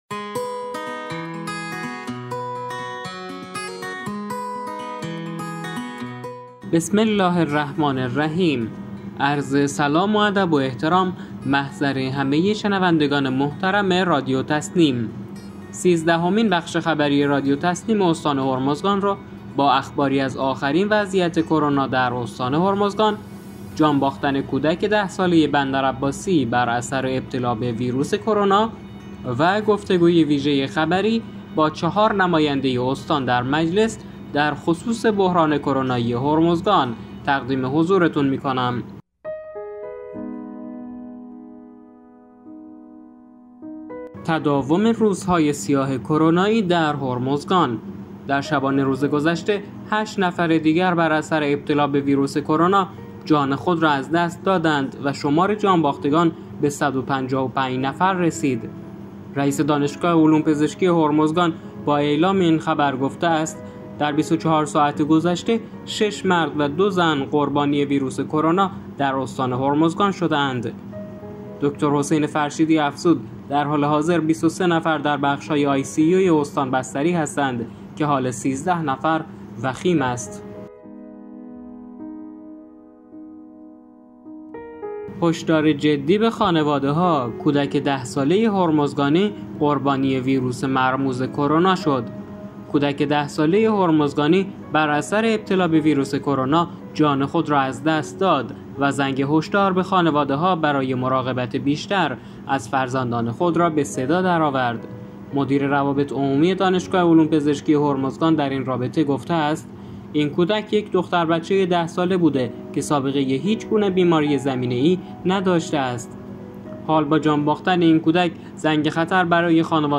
گروه استان‌ها-در سیزدهمین بخش رادیو تسنیم استان هرمزگان گفتگوی ویژه خبری با 4 نماینده استان در مجلس شورای اسلامی پیرامون بحران کرونایی هرمزگان خواهیم داشت.